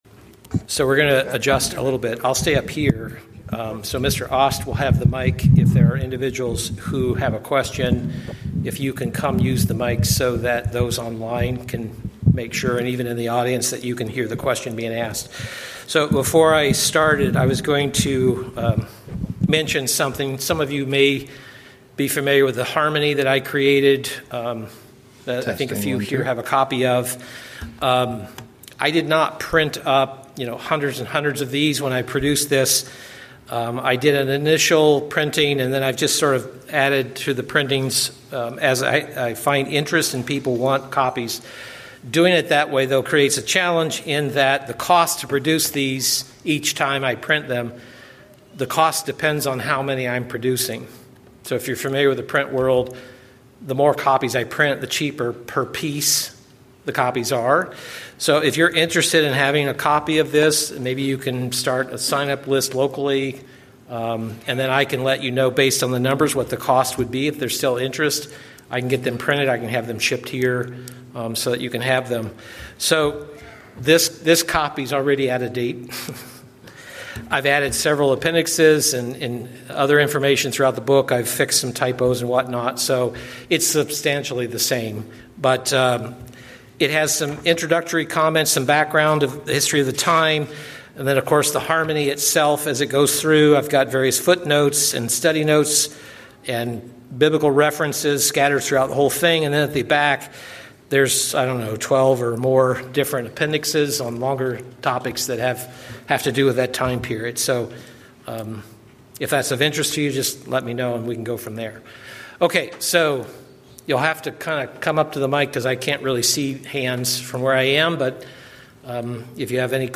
September 6, Question and Answer
Given in Atlanta, GA Buford, GA